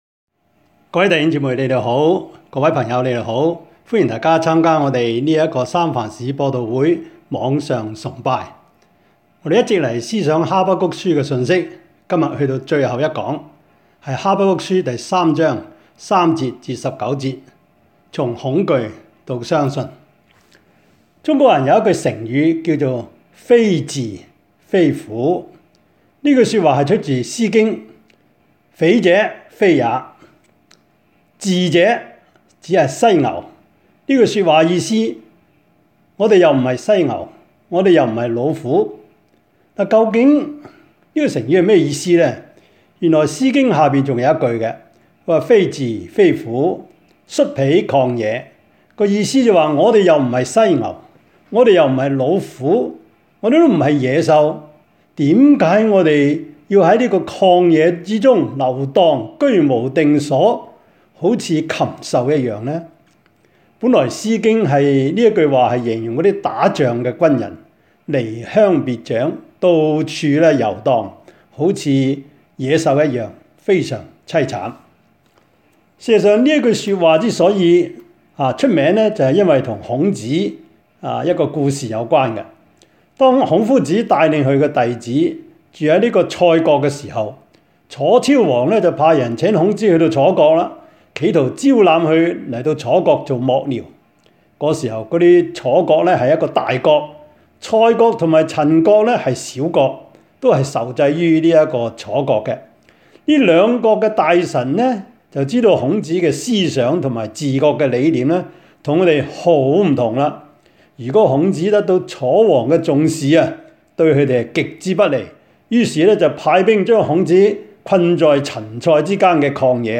疫症中之哈巴谷書講道系列
三藩市播道會主日網上崇拜